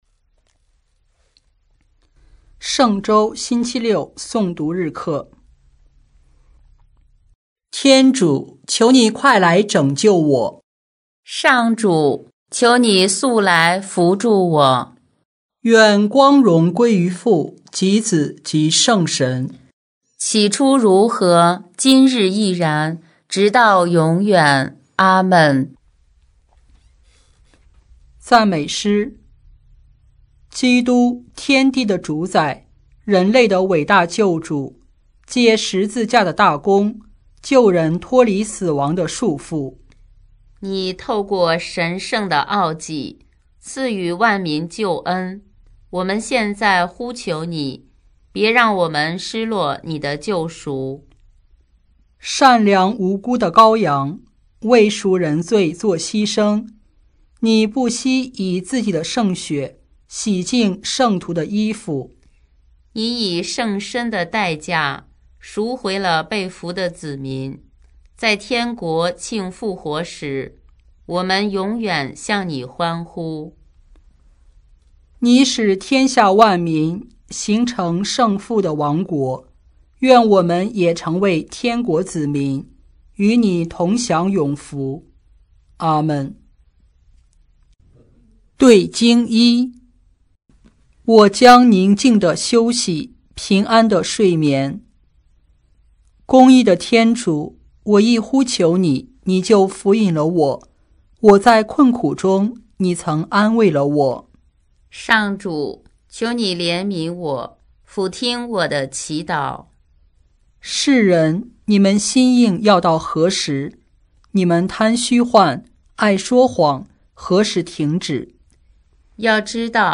【每日礼赞】|4月16日圣周六诵读